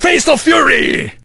el_primo_atk_01.ogg